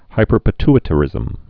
(hīpər-pĭ-tĭ-tə-rĭzəm, -ty-)